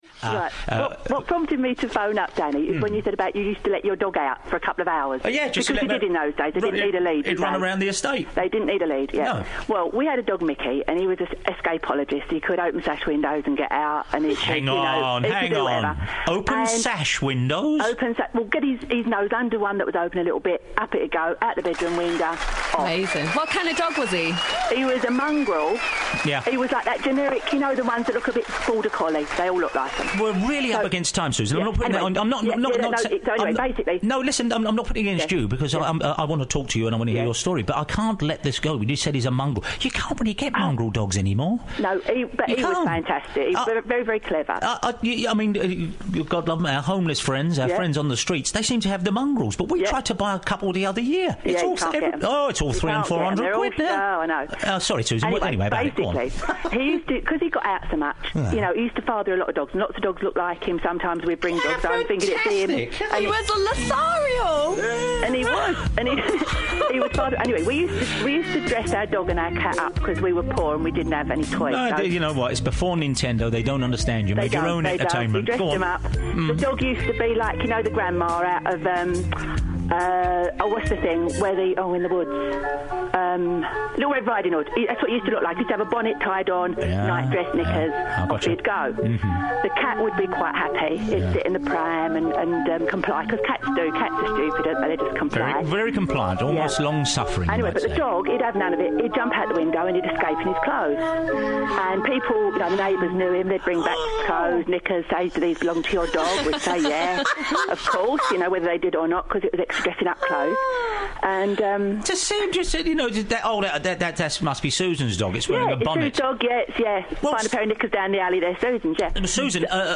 Top call from Danny Baker's 5Live radio show on Sat 28 Apr 2012 from the Cats v Dogs feature!